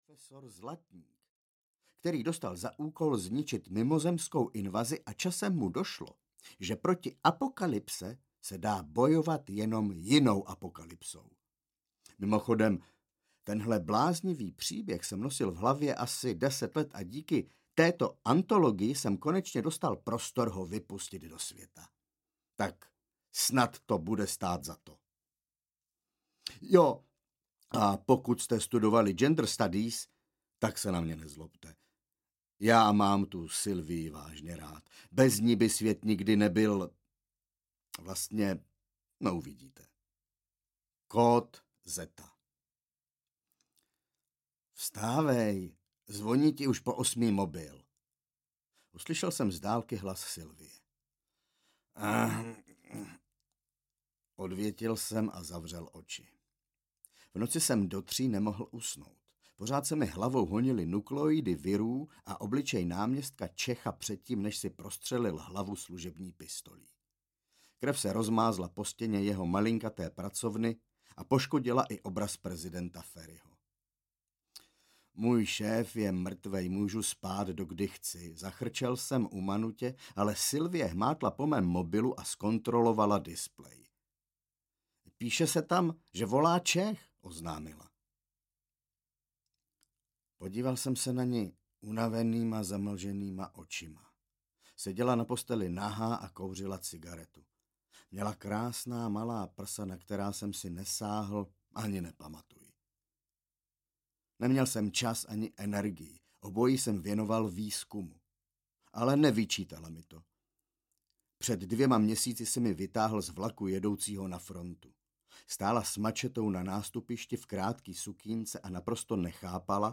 Kód Zeta audiokniha
Ukázka z knihy
kod-zeta-audiokniha